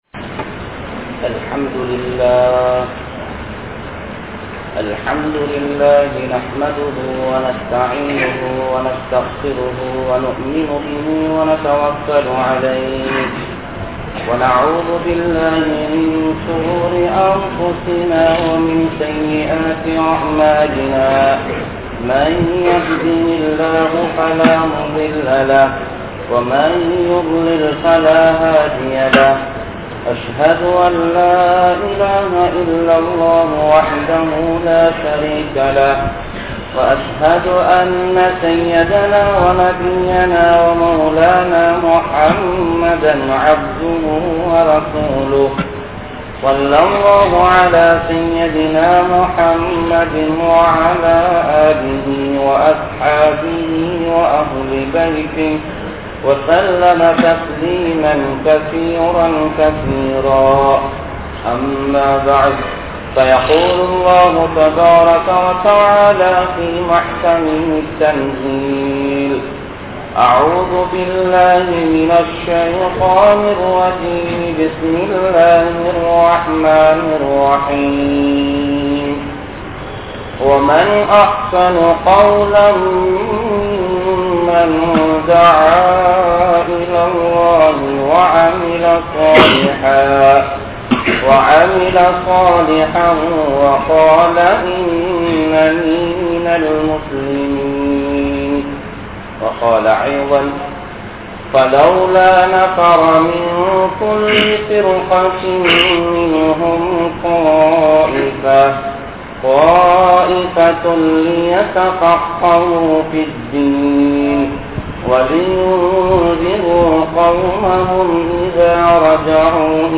Thyaahathin Vilaivuhal (தியாகத்தின் விளைவுகள்) | Audio Bayans | All Ceylon Muslim Youth Community | Addalaichenai